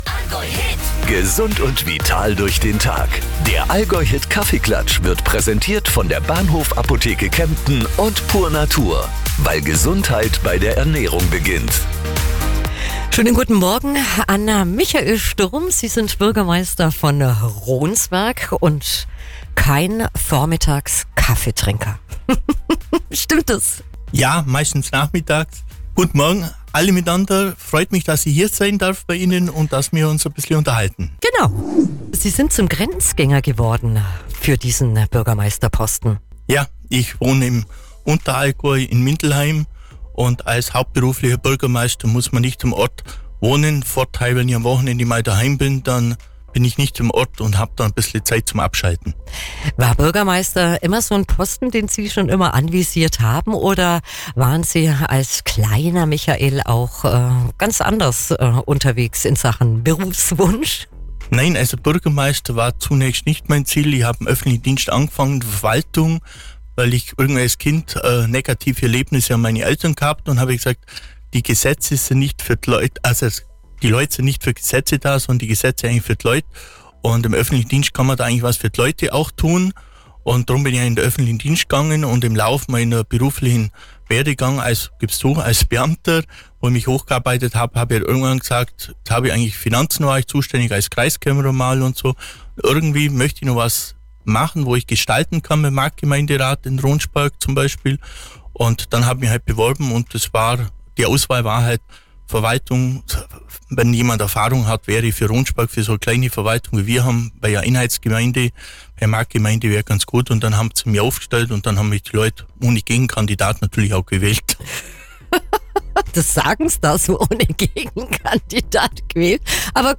Kaffeeklatsch 14.04.25: Michael Sturm, Bürgermeister von Ronsberg
Talk